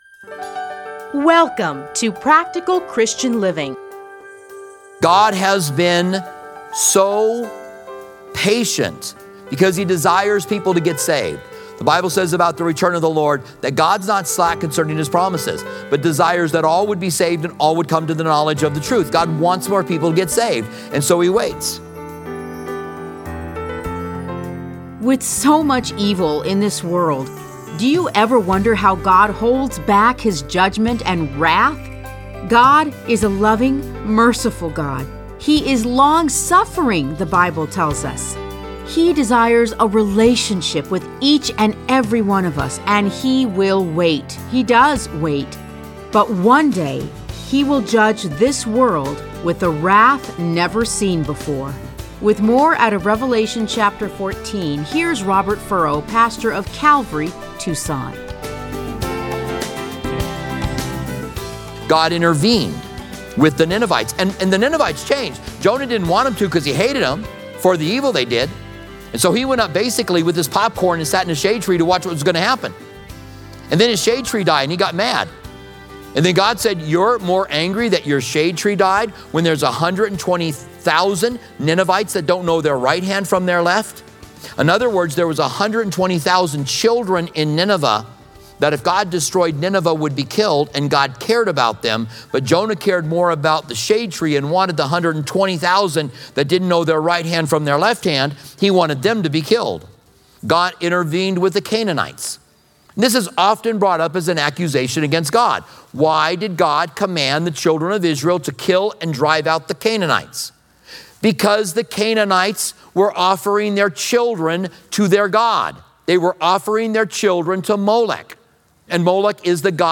Listen to a teaching from Revelation 14:14-20.